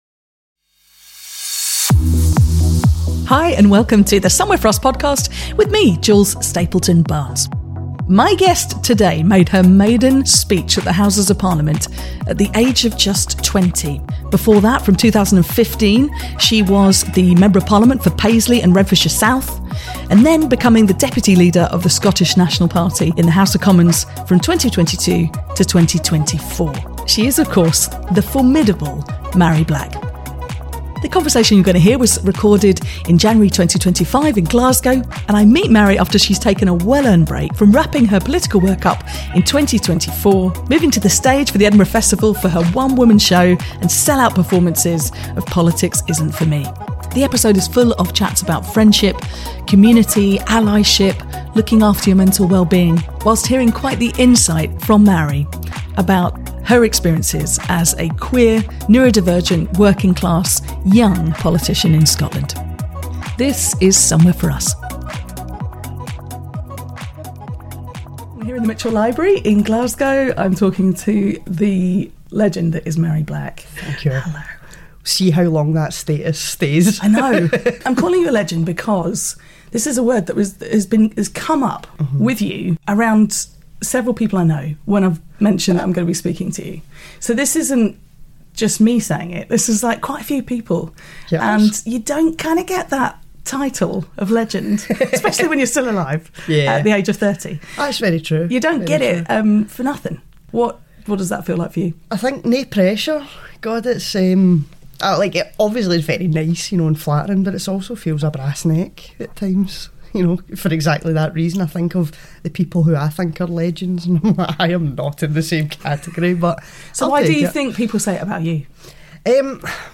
A warm, funny and revealing conversation with LGBTQ+ icon and once the UK's youngest politician since 1667, Mhairi Black.